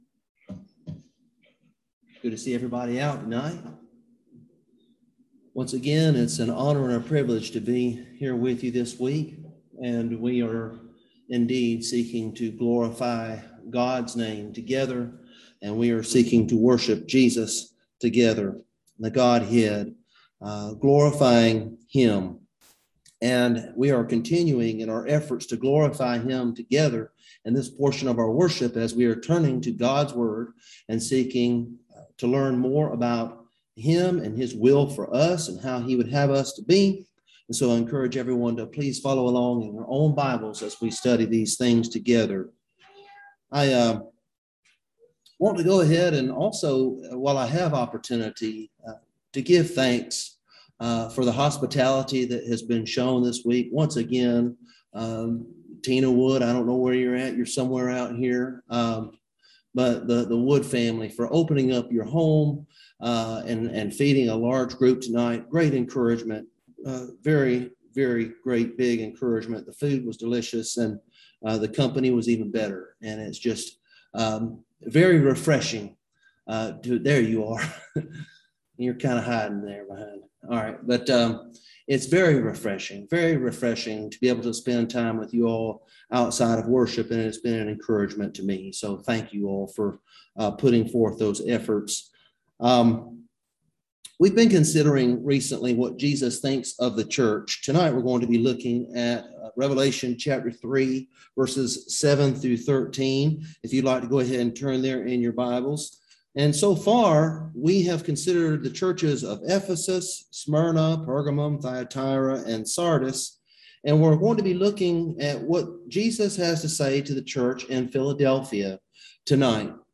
Passage: Revelation 3:7-13 Service Type: Gospel Meeting